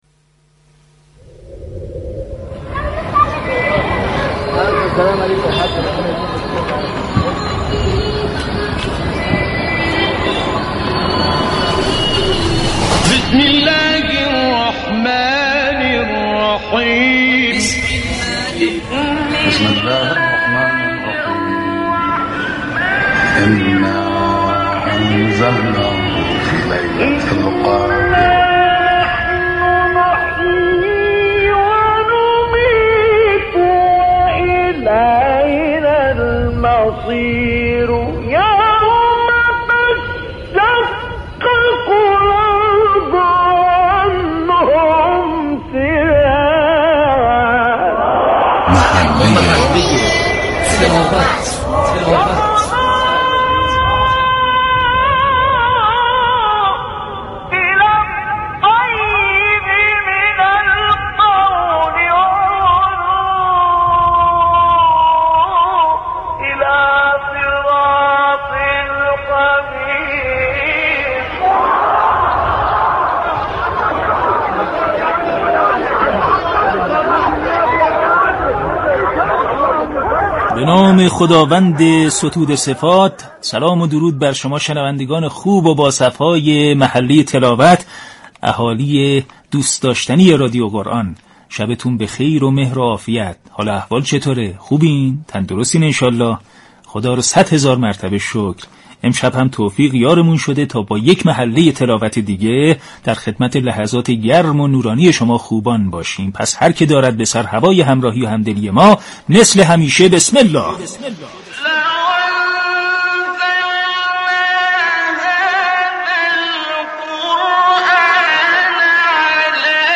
جنگ عصرگاهی رادیو قرآن با عنوان محله تلاوت كه پخش زیباترین تلاوت ها از قاریان مشهور جهان اسلام را همه روزه از ساعت 19 الی 20 به صورت زنده تقدیم شنوندگان می نماید.